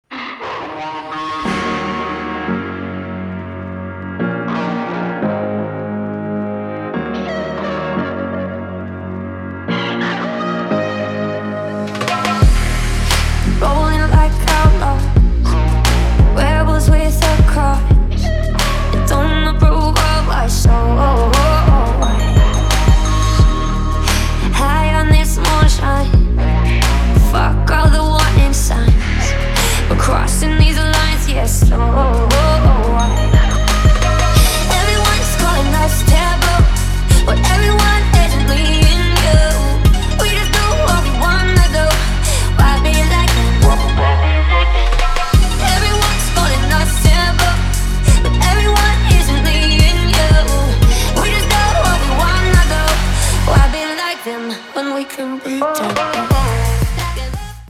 • Качество: 320, Stereo
женский вокал
Electronic
Midtempo